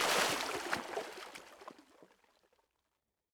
small-splash-5.ogg